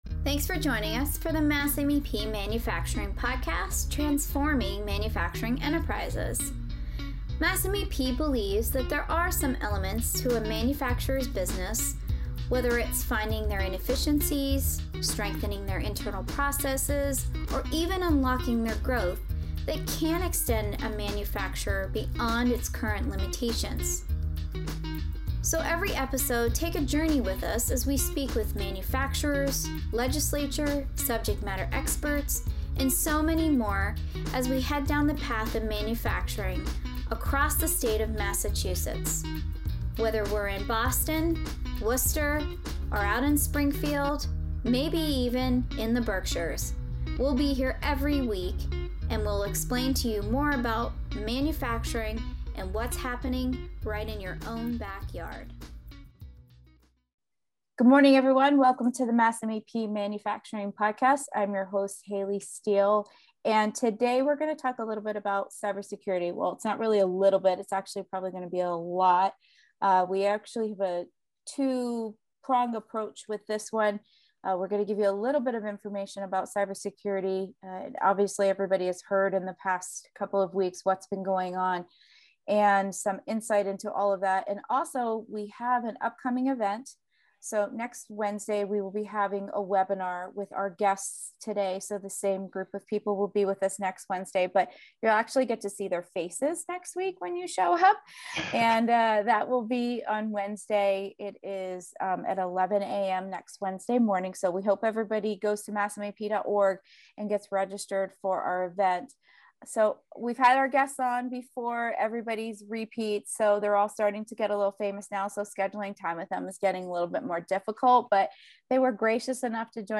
We had a great conversation with our Cybersecurity Whisperers